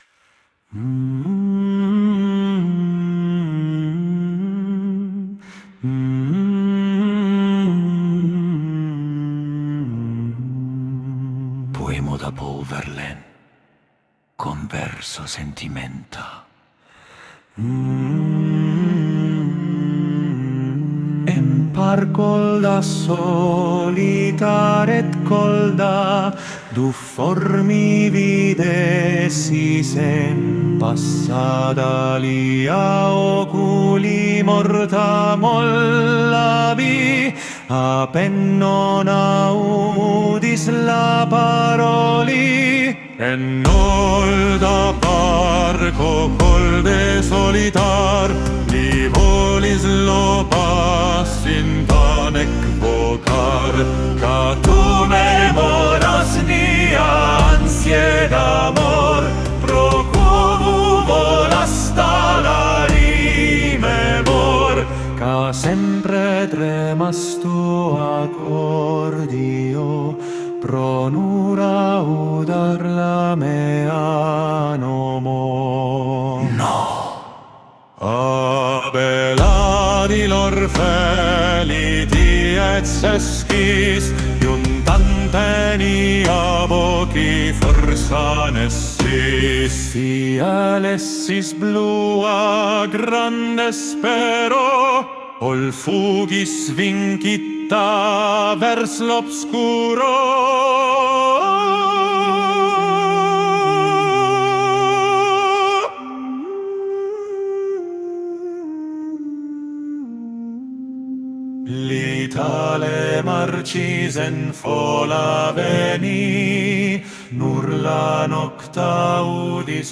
klasiko